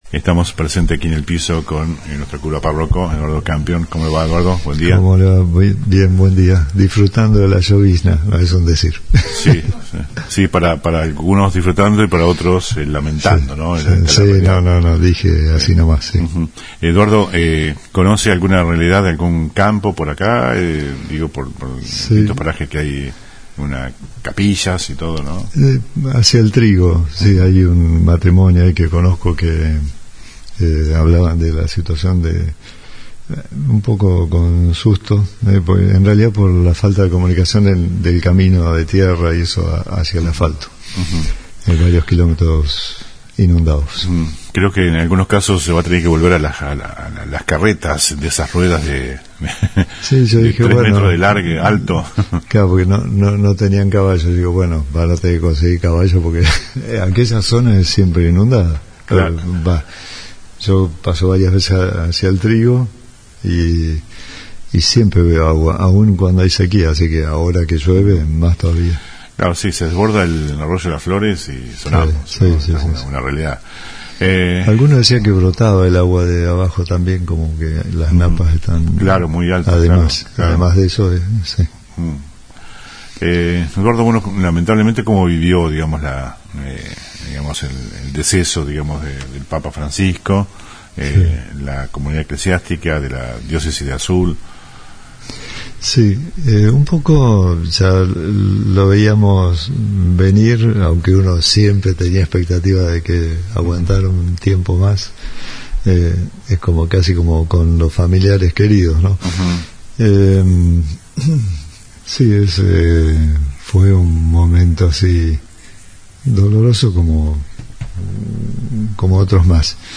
En la mañana de El Periodistico, conversamos con el cura párroco